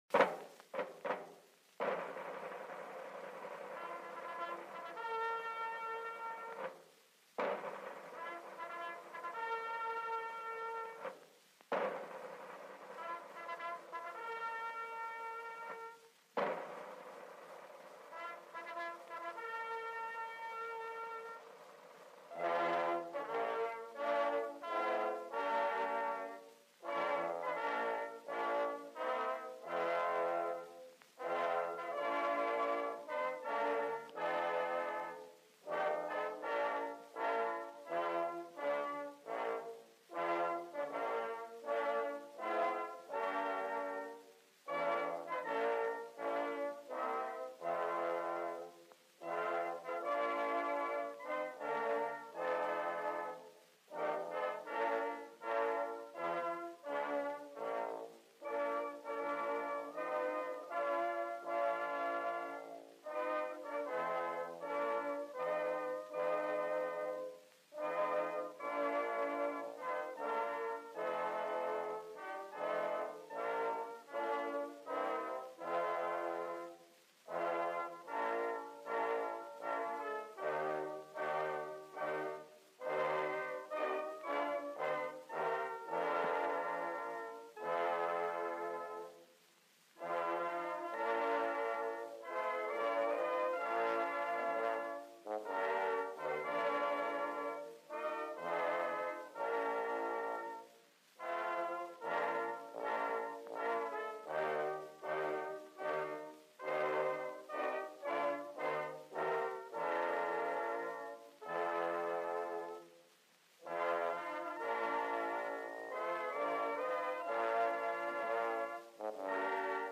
Short documentary of Agenda 2030 to open up public debates over the concept of Global Citizenship. Includes video of Obama at the U.N. on September 27 and propaganda clips of how we're being misled into going along with a Utopian vision of global control.